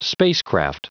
Prononciation du mot spacecraft en anglais (fichier audio)
Prononciation du mot : spacecraft